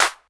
Snare_11.wav